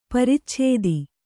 ♪ paricchēdisu